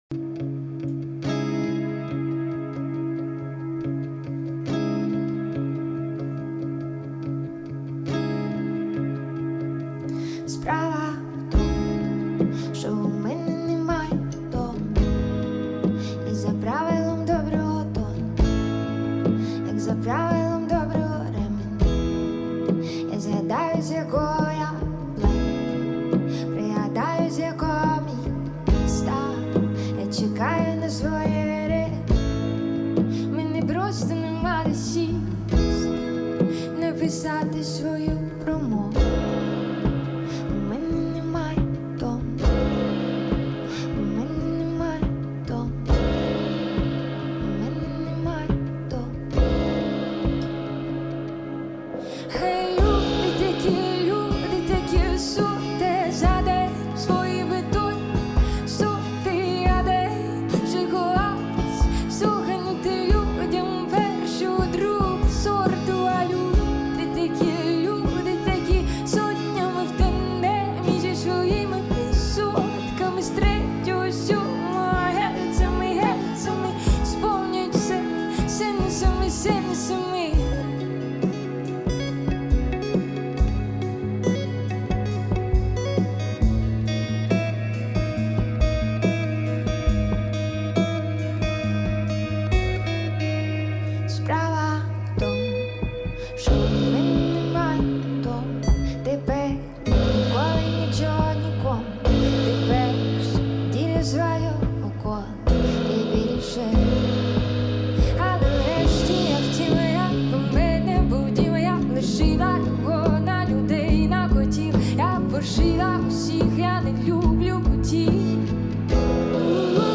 Такт 4/4 (Темп 70)